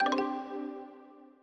Longhorn 2000 - Chimes.wav